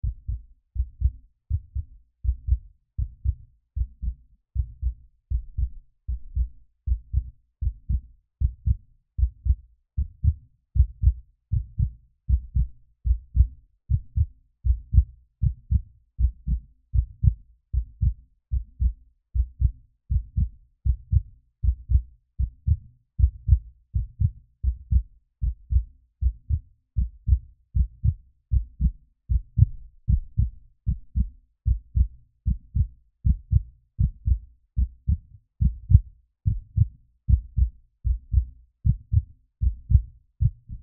Beating Heart long clip
Tags: Halloween Halloween sounds Halloween ambience suspense sounds hearbeat sound creaky door sounds suspense creaking door